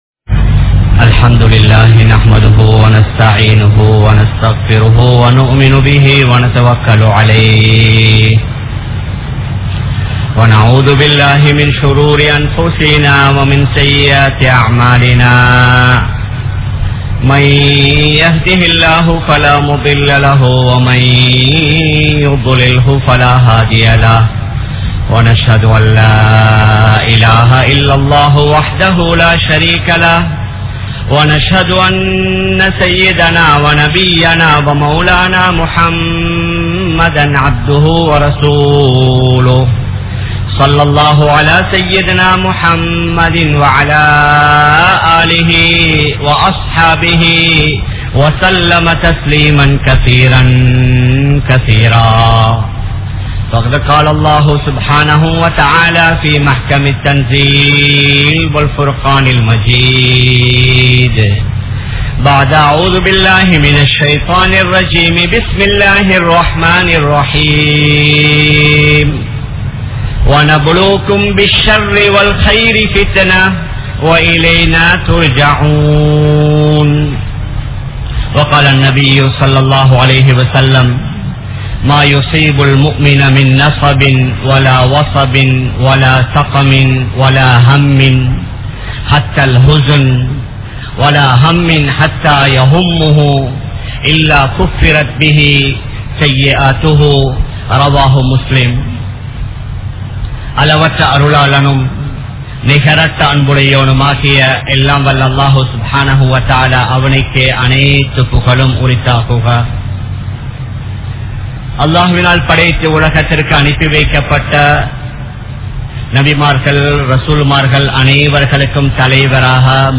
Soathanaihal(சோதனைகள்) | Audio Bayans | All Ceylon Muslim Youth Community | Addalaichenai
Kollupitty Jumua Masjith